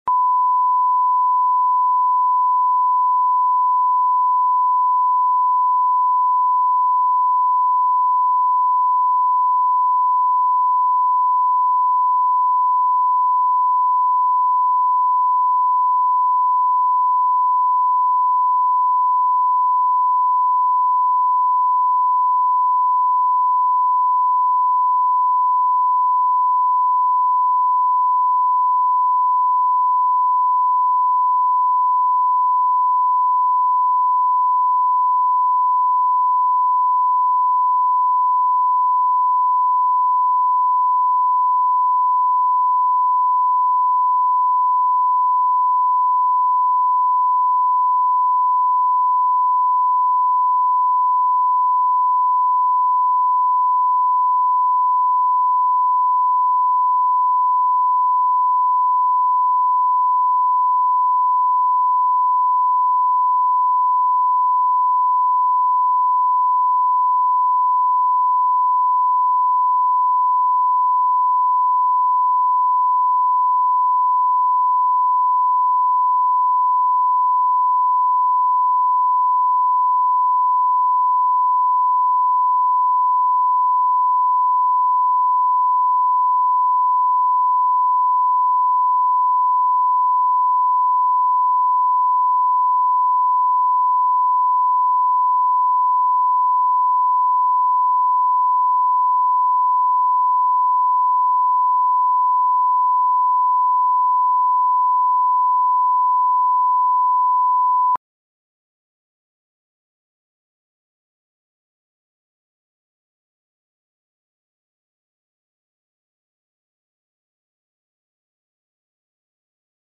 Аудиокнига Своя судьба | Библиотека аудиокниг